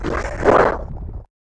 Index of /App/sound/monster/chaos_ghost
attack_act_1.wav